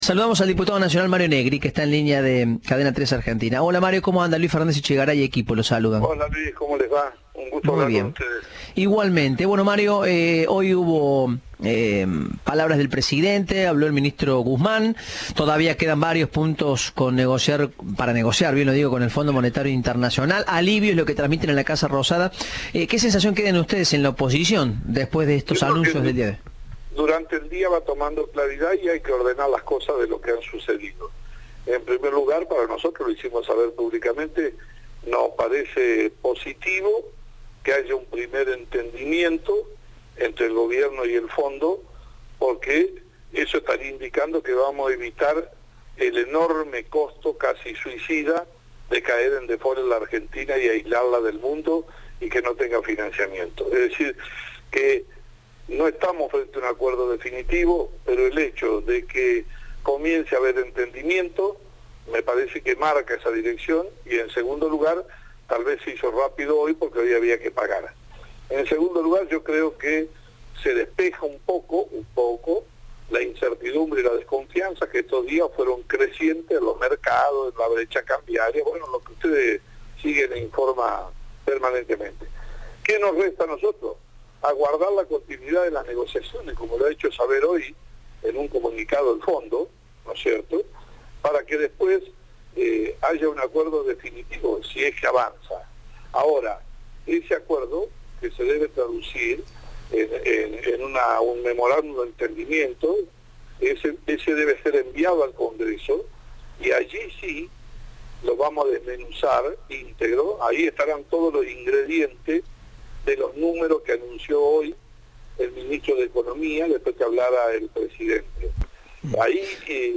El presidente del bloque de Diputados de la UCR, celebró en diálogo con Cadena 3 el acuerdo con el Fondo Monetario, pero anticipó que no acompañarán ningún plan que plantee suba de impuestos.